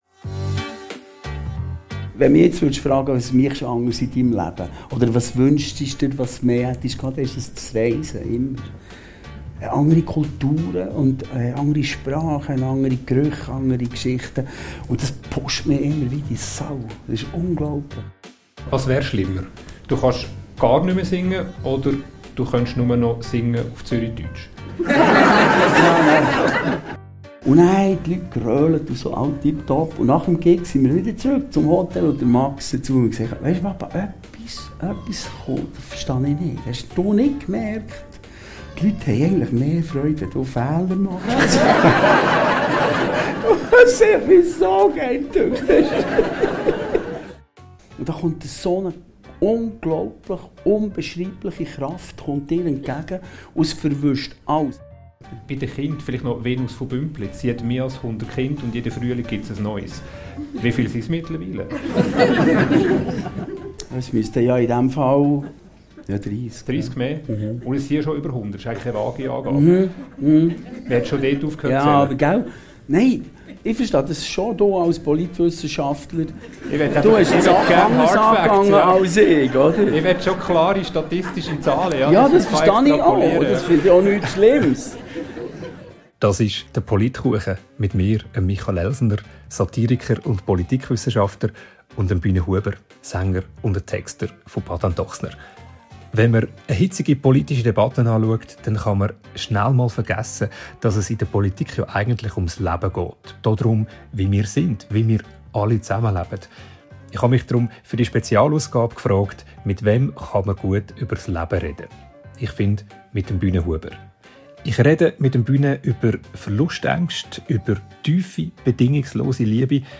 Ich spreche mit ihm über Verlustängste, über tiefe bedingungslose Liebe, seine Rituale und darüber, wie er Arbeitsplätze in der Medienbranche sichert. Wir haben dieses Gespräch im Januar 2025 geführt.